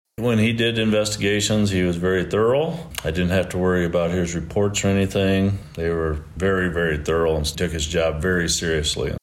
The news was released at the Humboldt County Board of Supervisors session Monday morning.
Sheriff Kruger says he leaves big shoes to fill in the office.